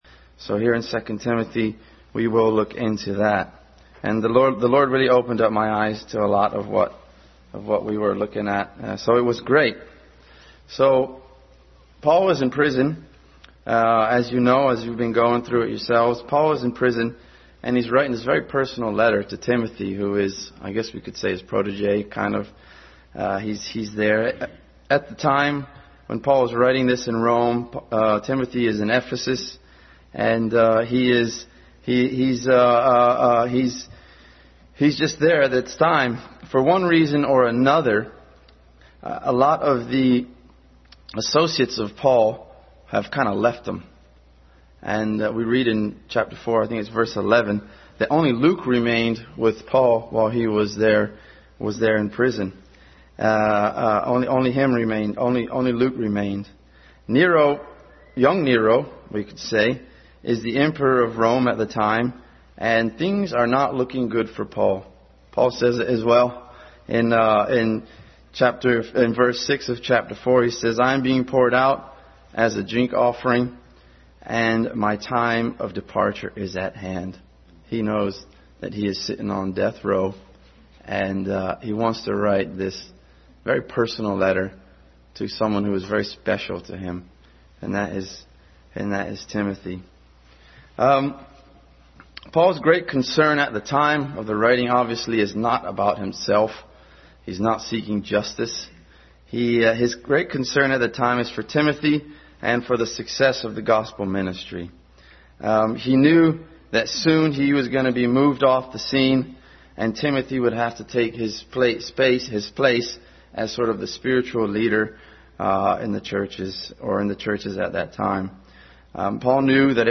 2 Timothy 2:1-10 Passage: 2 Timothy 2:1-10, 1:6-12 Service Type: Family Bible Hour